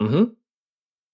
Все файлы записаны четко, без фоновых шумов.
Звук согласия с кивком и ыгы ртом